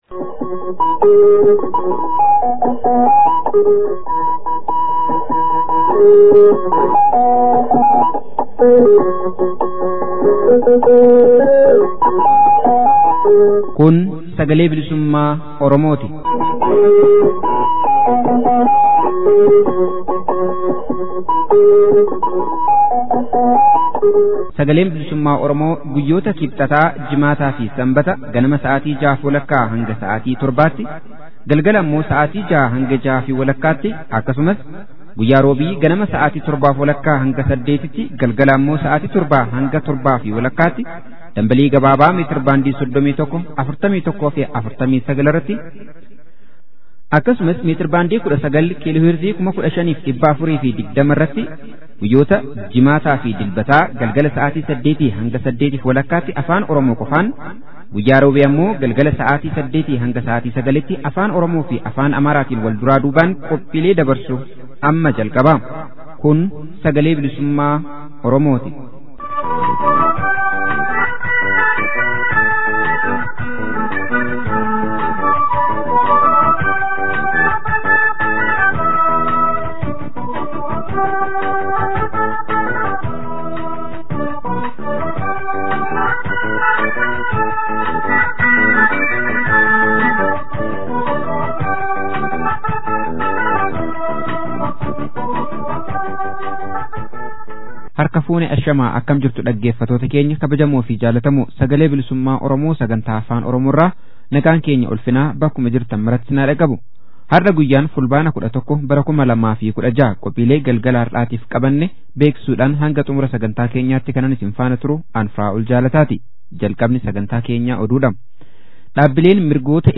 SBO Fulbaana 11,2016. Oduu